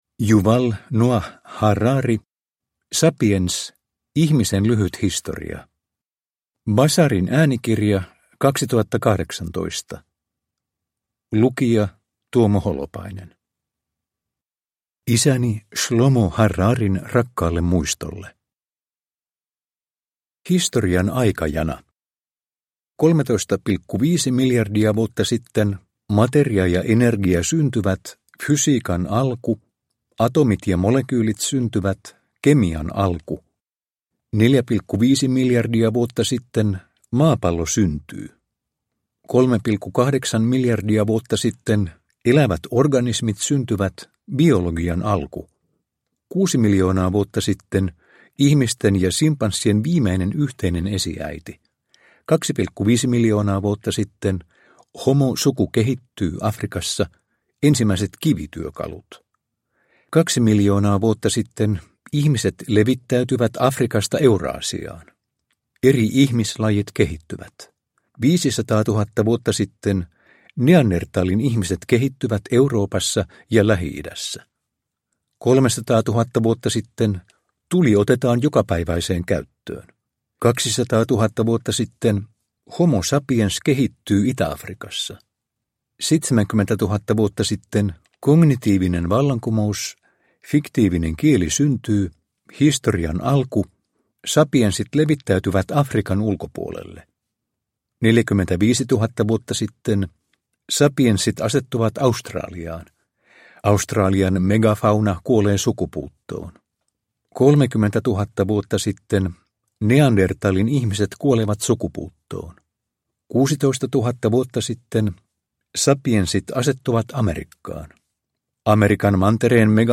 Sapiens – Ljudbok – Laddas ner